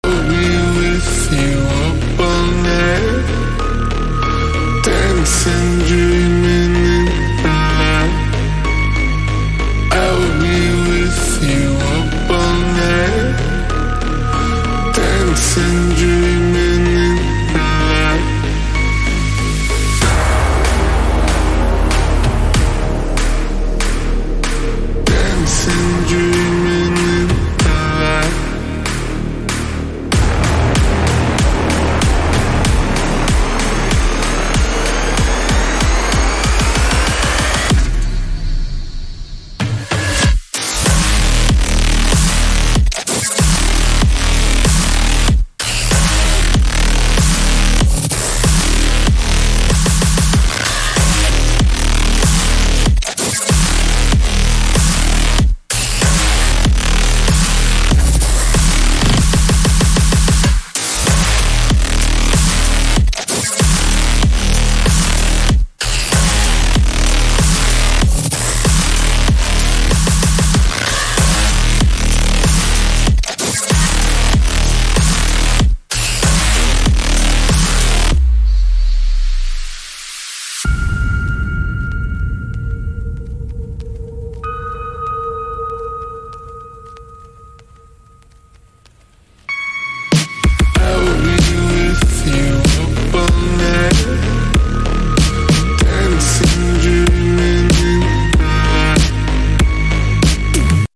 【Midtempo Bass风格Serum预设包】EDM Templates Dopamine – Pro Midtempo Soundbank
专业制作的中速声音的高级精选。
Monstrous Growls
Heavy Midtempo Basses
Powerful Drop Synths
Post-Industrial Atmospheres
Aggressive Bass One Shots
Dark Reese Basses
Tech-Noir Intro Synths